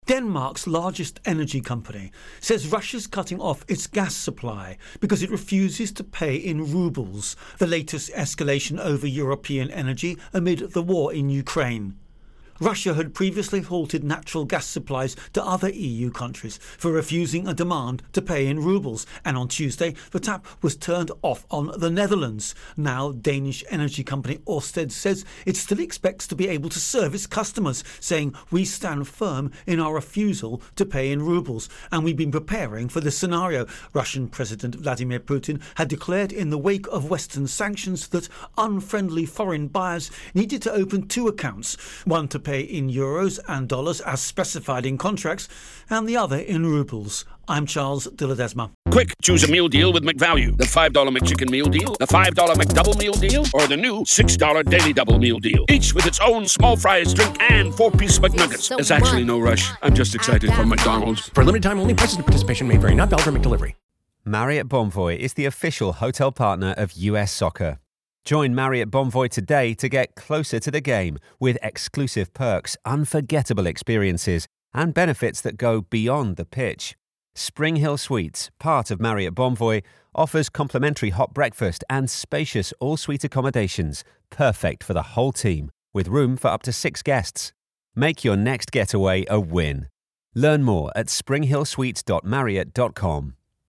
Denmark Russia Gas Intro and Voicer